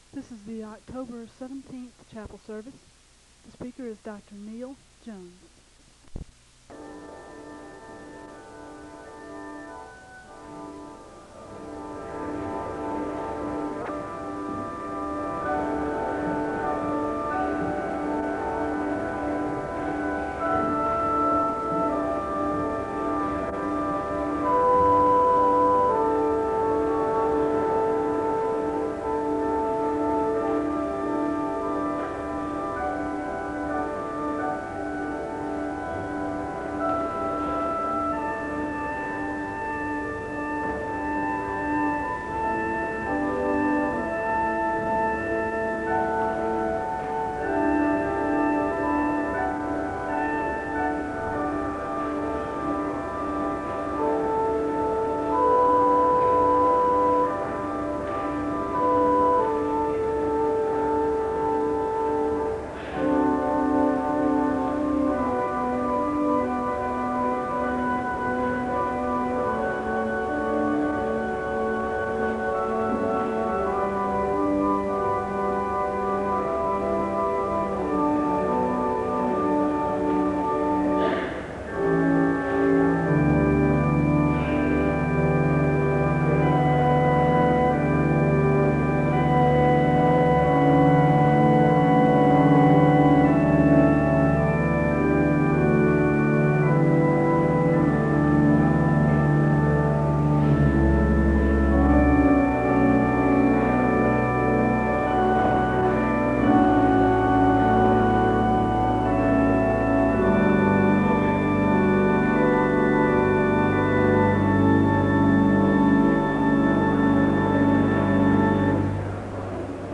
The service begins with organ music (00:00-04:50).
The choir sings the anthem (11:53-14:08).
The choir sings a song of worship (39:16-41:50).